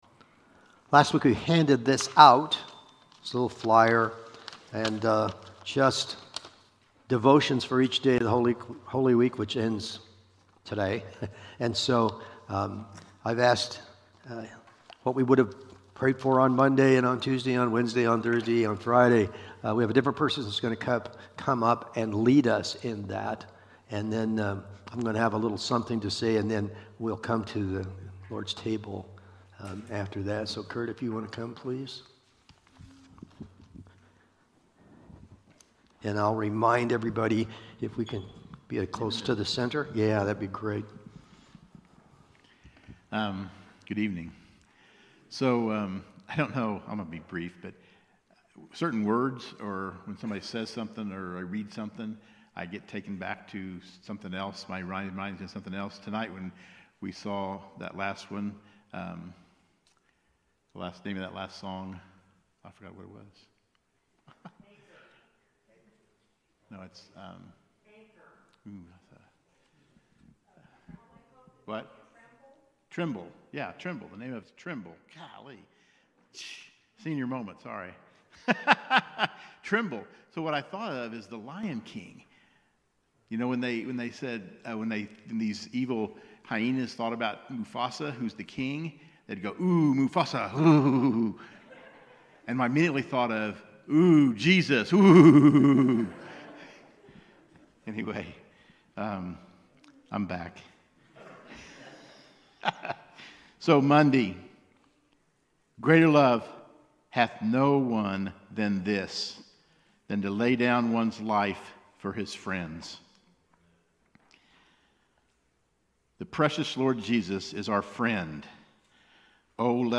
Service Type: Holiday Sermons